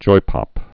(joipŏp)